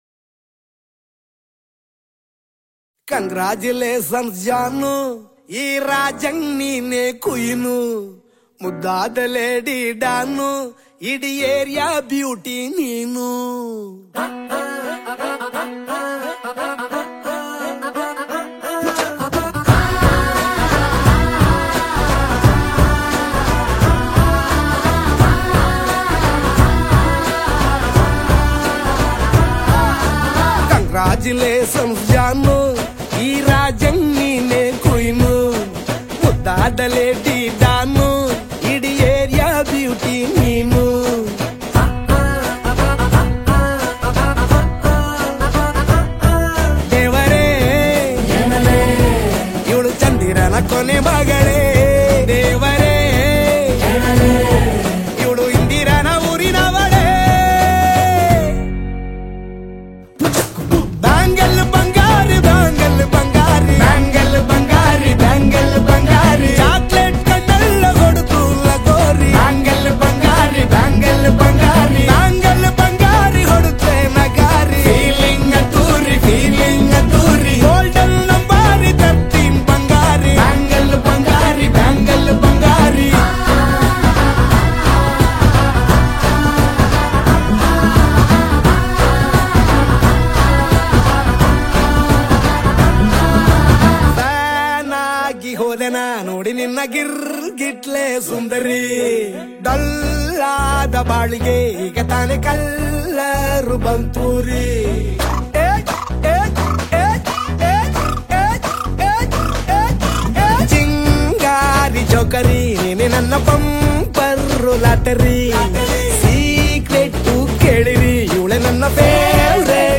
romantic, energetic and youthful Kannada song
The music feels catchy and vibrant.